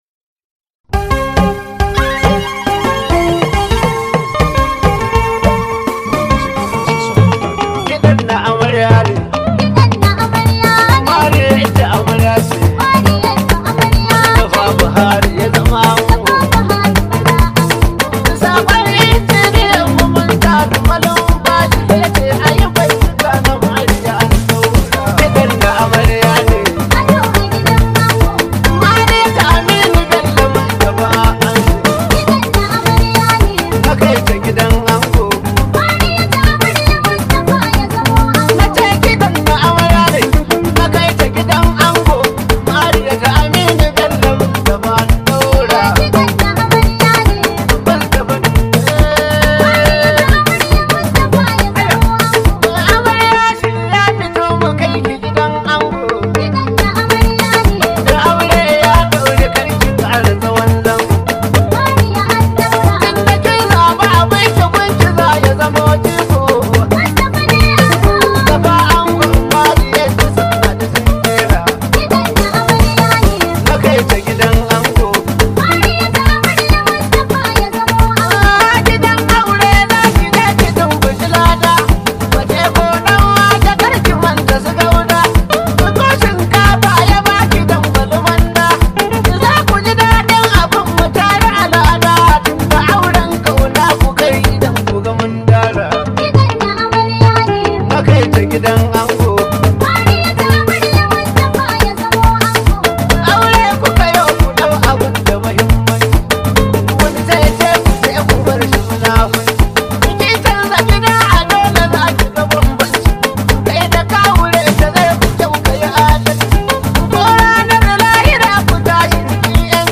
Hausa Musician